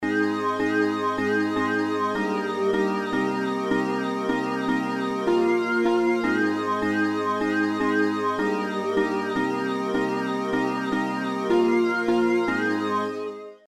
chording